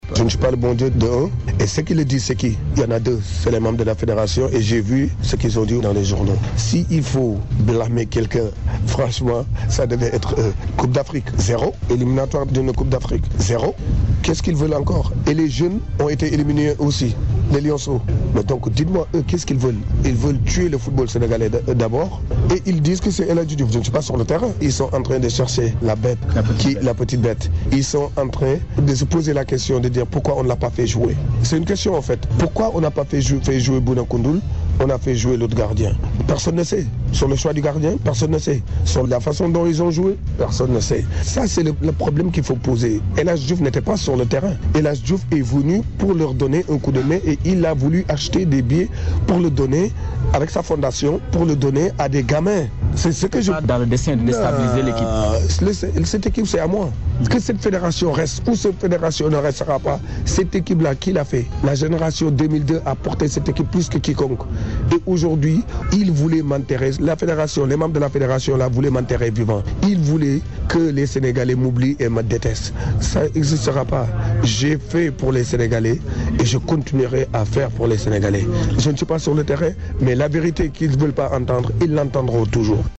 Il a tenu ses propos sur les ondes de la Rfm.